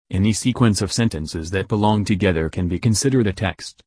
Convert Text To Speech
• It offers natural-sounding voices for text to speech conversion.